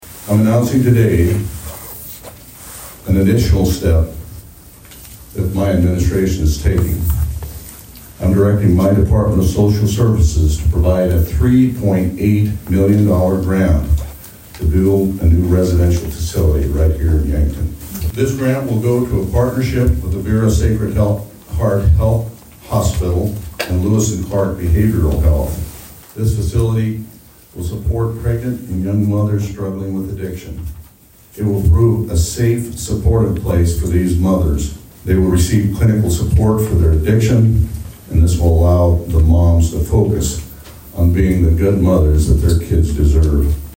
Rhoden made the announcement at the grand opening of the new Lewis & Clark Behavior Health Services facility in Yankton on Wednesday.